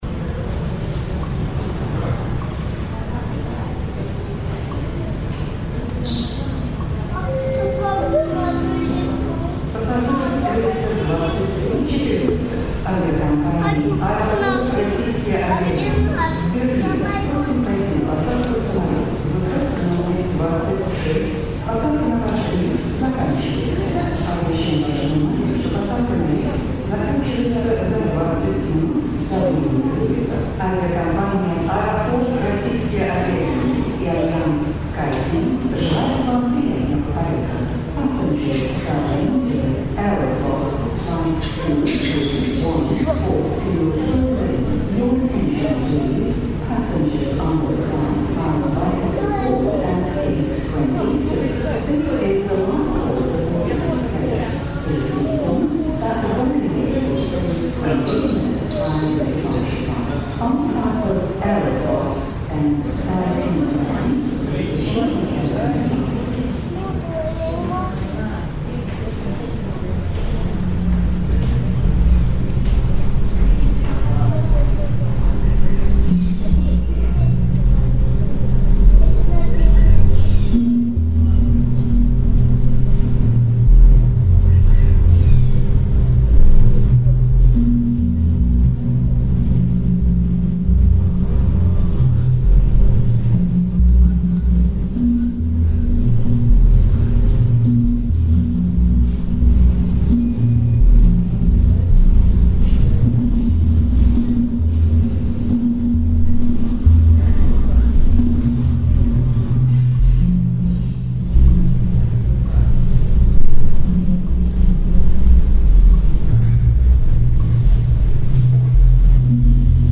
Airports and stations - Memoryphones installation
Part of the city-wide Memoryphones installation in Oxford, 20-21 November 2015.